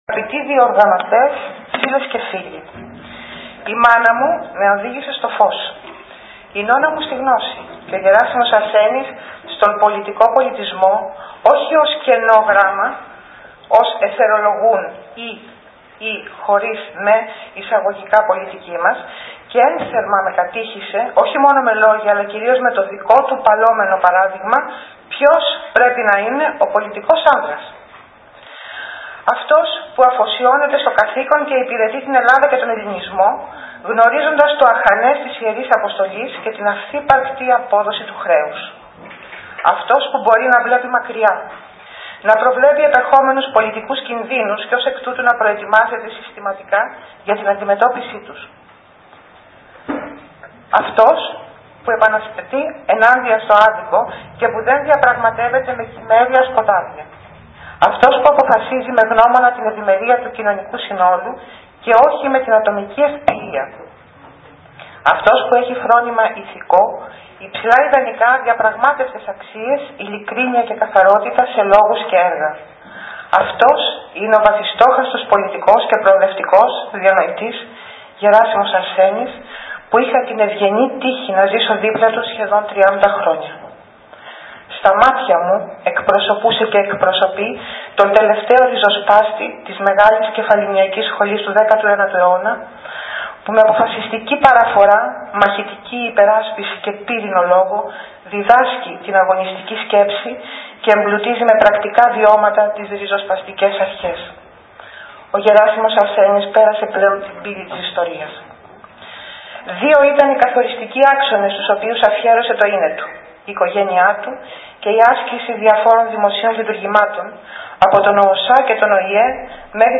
Πρώτη μέρα του Συνεδρίου των Αποδήμων.